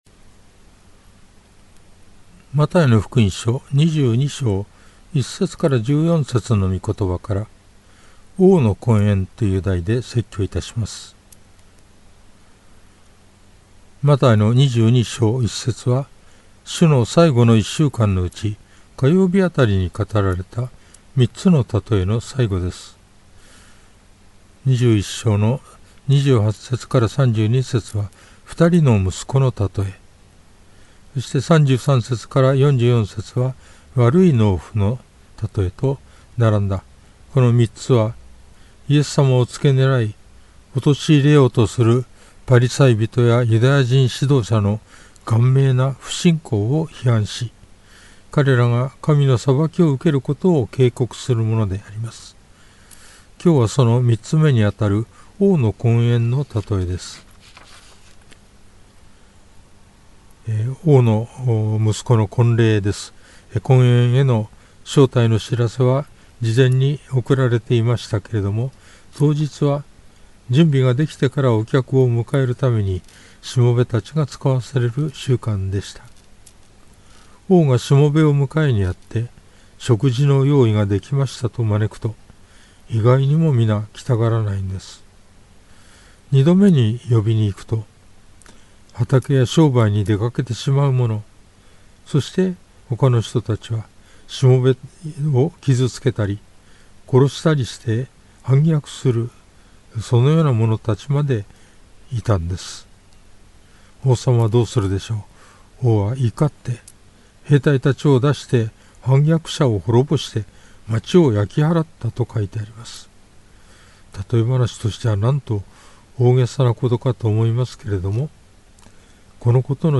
主日礼拝
説教
♪ 事前録音分